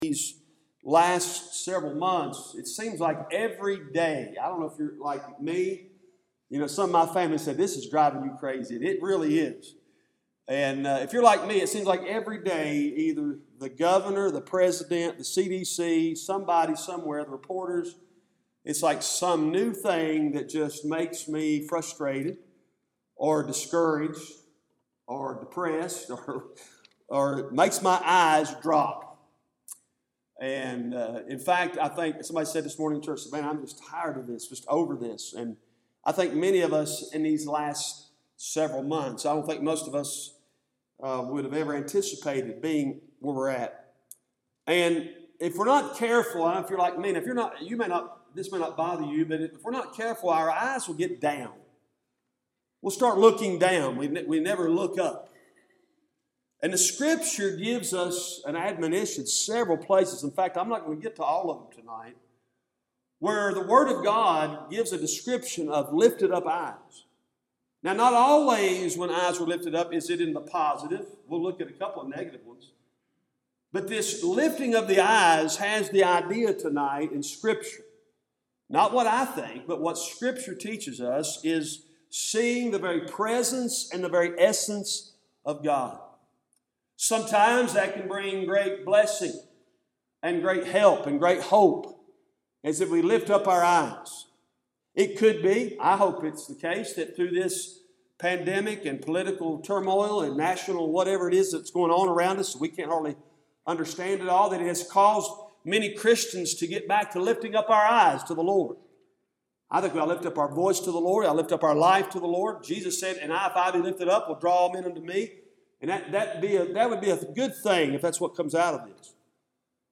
Cooks Chapel Baptist Church Sermons